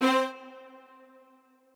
strings9_8.ogg